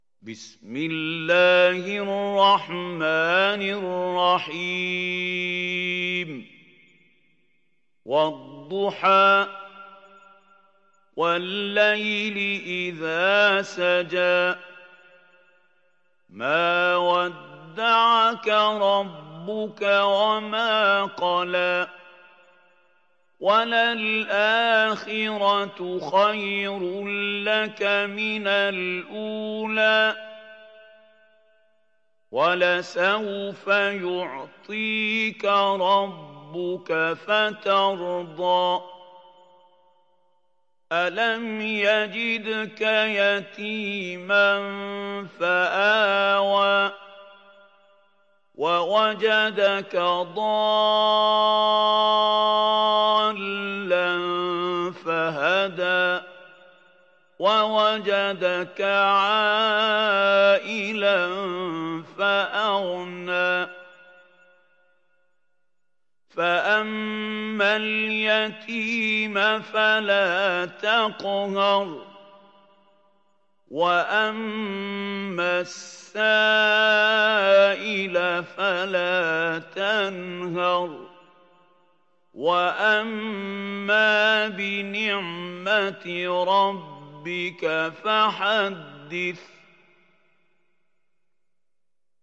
Surat Ad Duhaa Download mp3 Mahmoud Khalil Al Hussary Riwayat Hafs dari Asim, Download Quran dan mendengarkan mp3 tautan langsung penuh